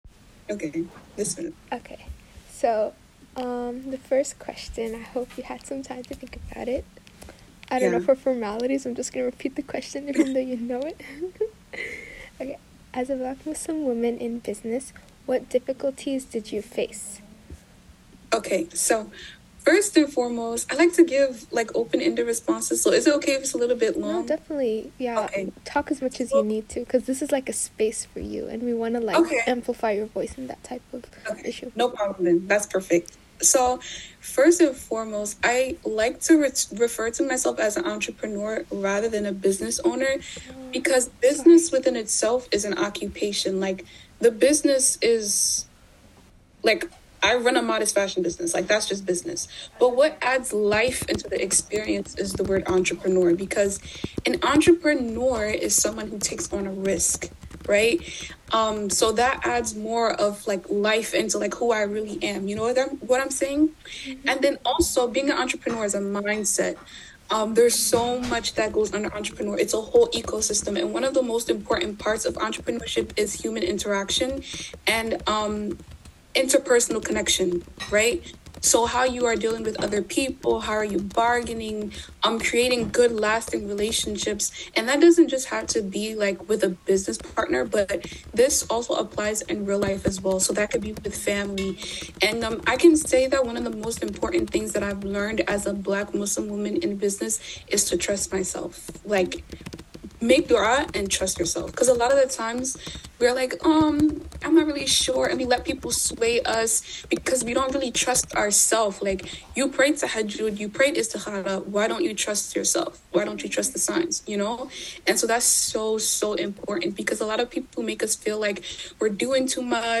Here are a few snippets from the interview that I found illuminating: